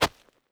DIRT.4.wav